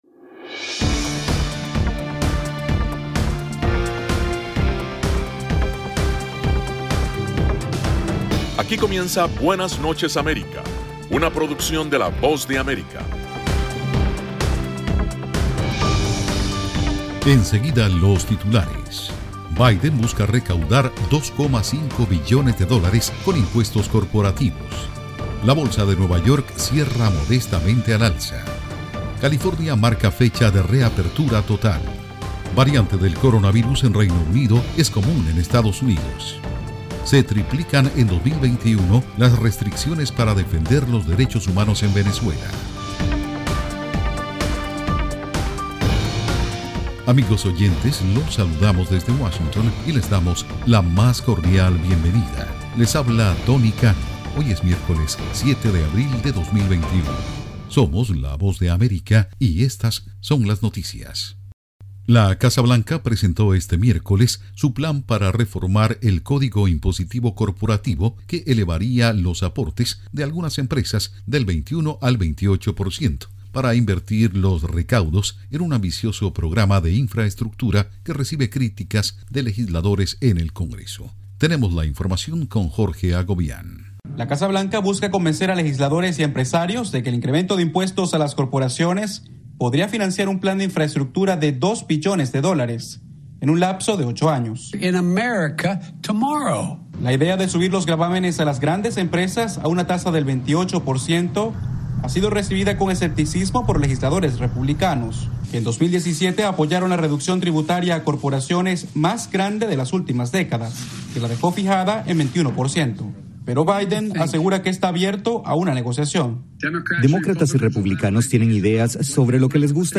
PROGRAMA INFORMATIVO BUENAS NOCHES AMERICA.